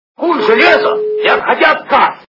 » Звуки » Из фильмов и телепередач » Бриллиантовая рука - Куй железо не отходя от кассы
При прослушивании Бриллиантовая рука - Куй железо не отходя от кассы качество понижено и присутствуют гудки.